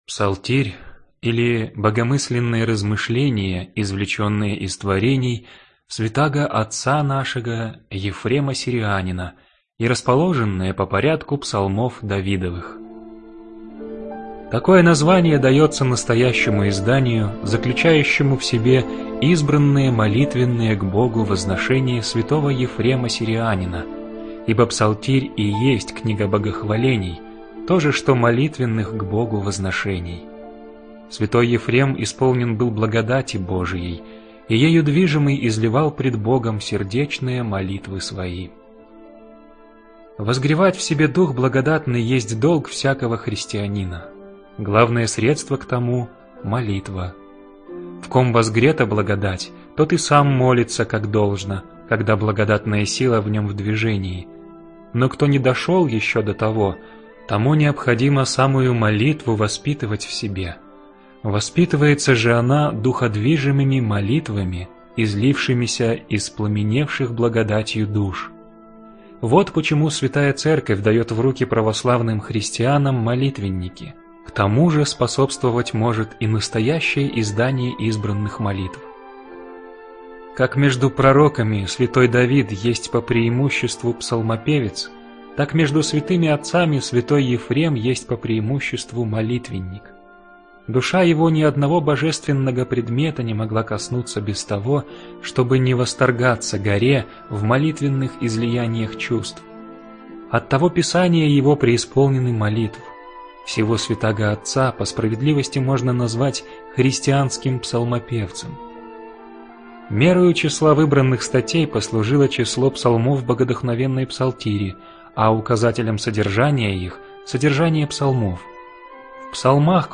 Аудиокнига Псалтирь | Библиотека аудиокниг